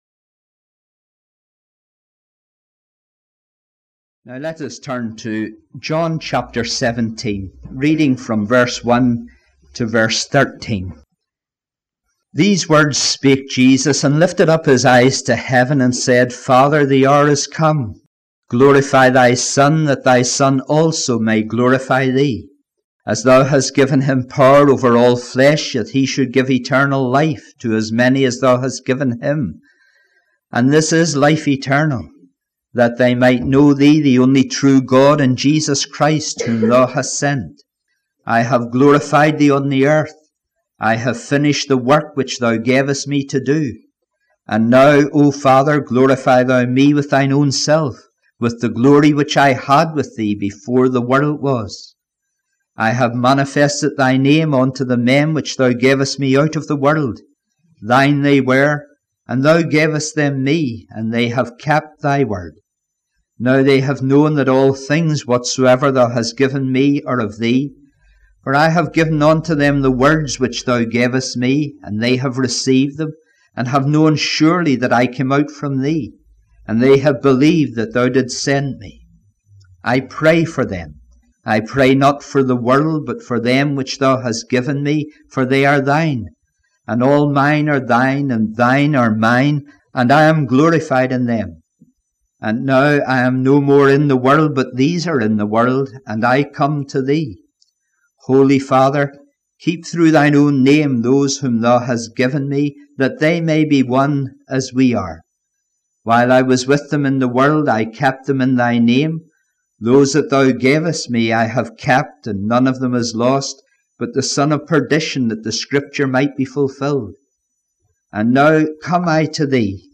Matthew chapter 26 verses 26 to 30 — “A Communion Sermon”